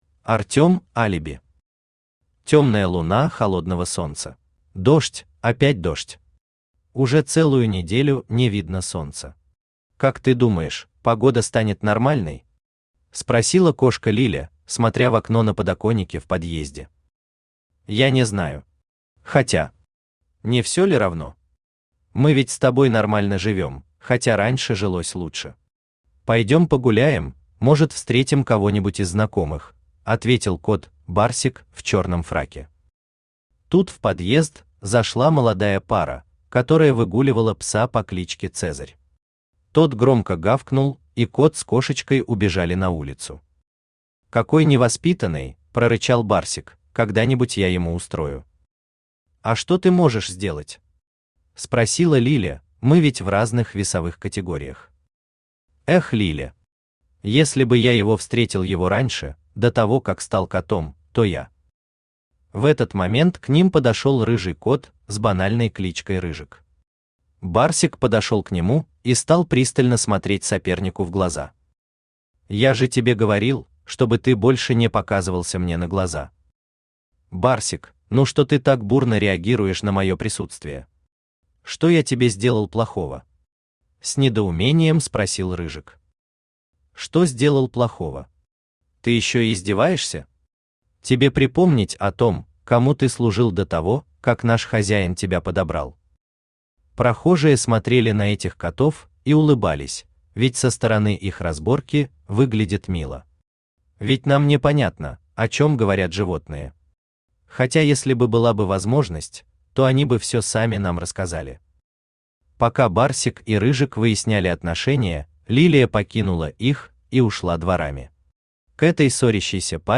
Аудиокнига Темная луна холодного солнца | Библиотека аудиокниг
Aудиокнига Темная луна холодного солнца Автор Артем Алиби Читает аудиокнигу Авточтец ЛитРес.